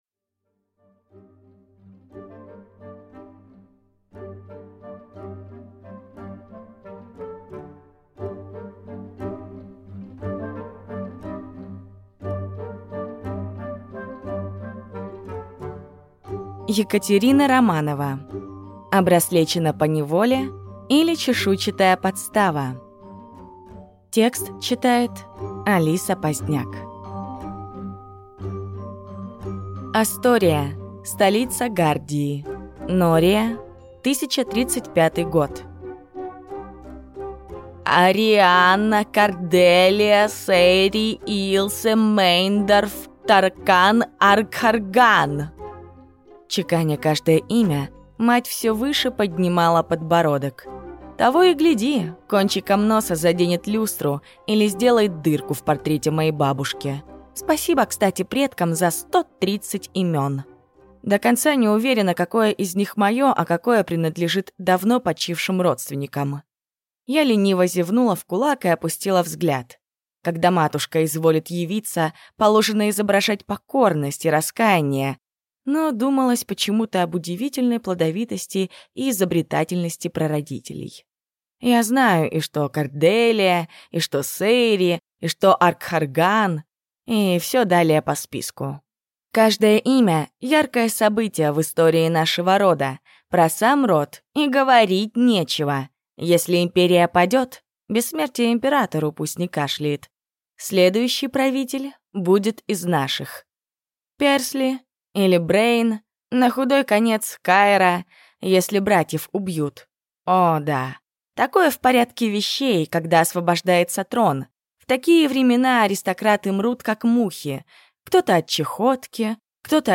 Аудиокнига Обраслечена поневоле, или Чешуйчатая подстава | Библиотека аудиокниг